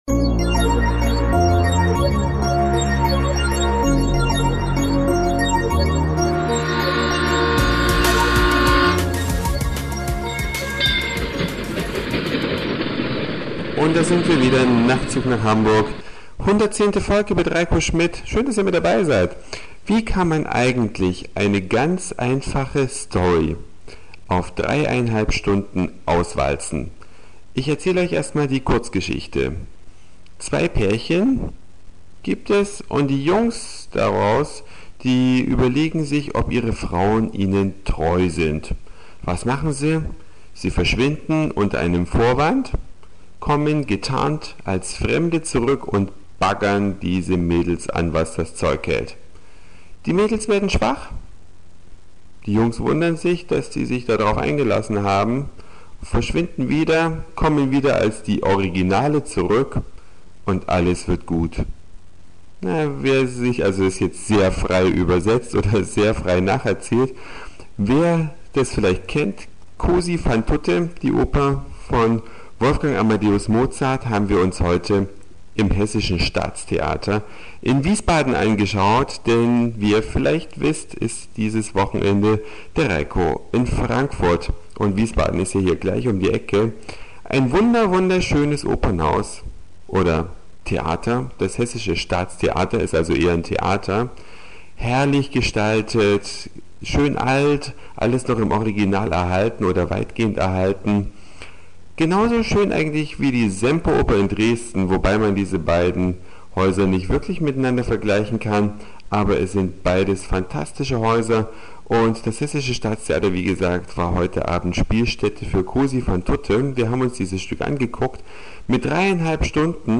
Eine Reise durch die Vielfalt aus Satire, Informationen, Soundseeing und Audioblog.
Die Kleidung der Leute heute im hessischen Staatstheater